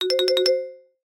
comedy_marimba_flutter_or_shake_short_low_pitch